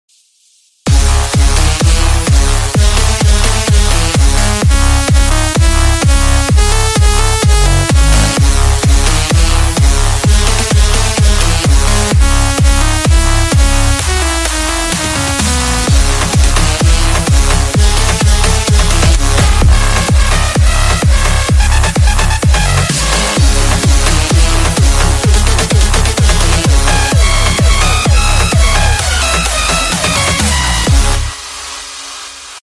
HARD WELL GENOS.mp3 באורגן כמובן…